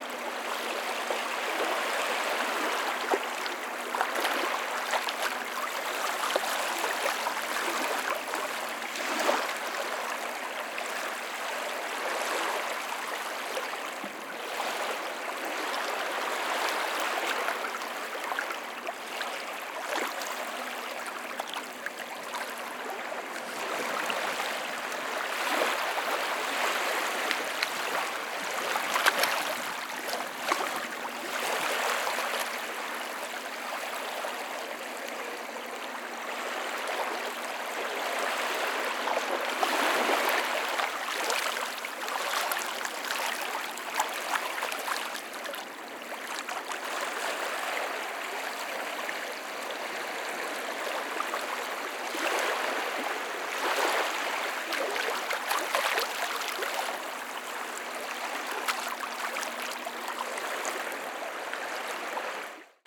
Gentle Sea On Flat Beach
Category 🌿 Nature
beach environmental-sounds-research field-recording gurgle lapping ocean ripple sea sound effect free sound royalty free Nature